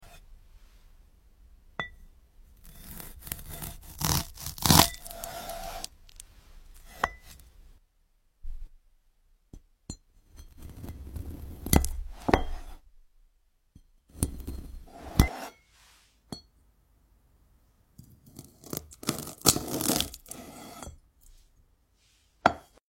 Glass passion fruit. Starfruit like sound effects free download
Exotic cuts you’ve never seen before. Wait for the slice… it’s pure ✨ satisfaction.